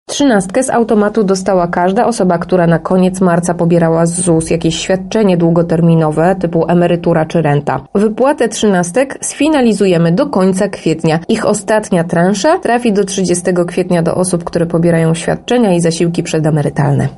– mówi